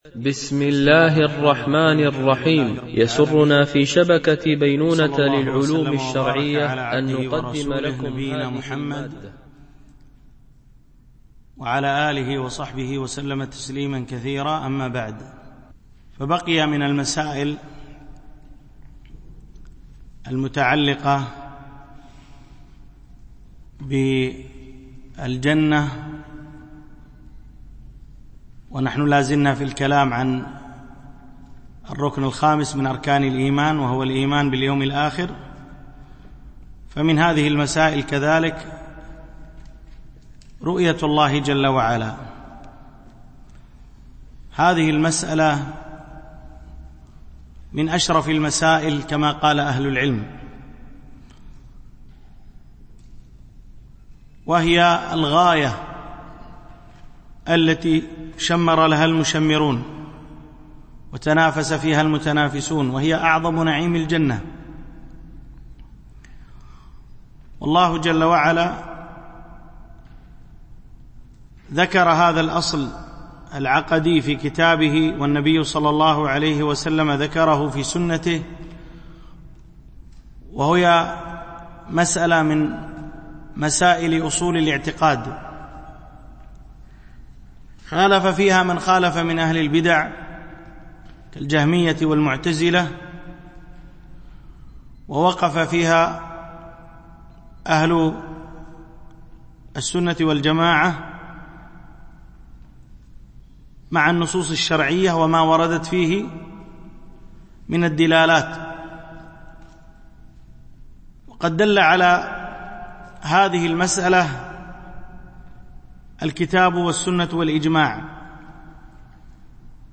شرح حديث جبريل في بيان مراتب الدين - الدرس 15